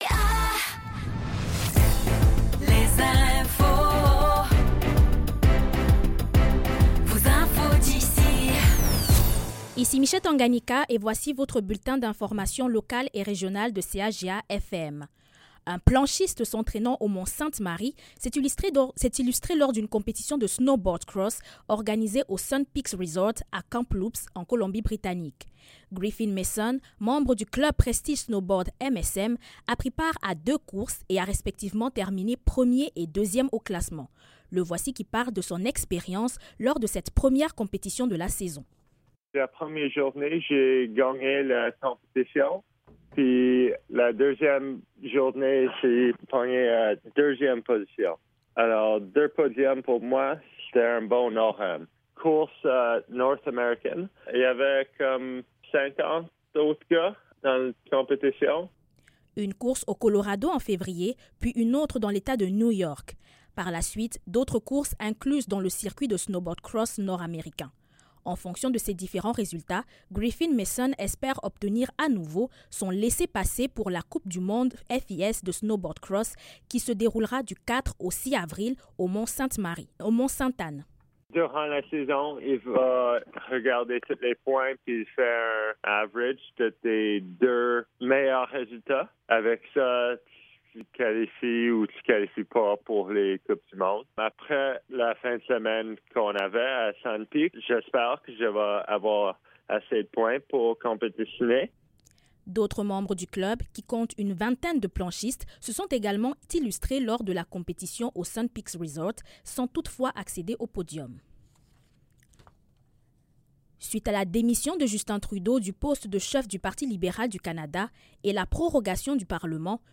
Nouvelles locales - 9 janvier 2025 - 15 h